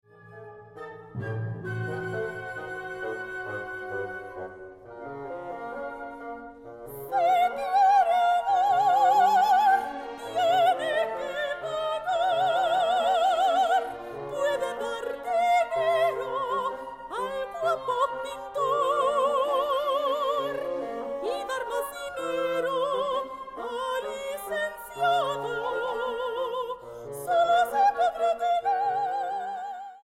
Grabado en: Teatro Aguascalientes, marzo, 2013.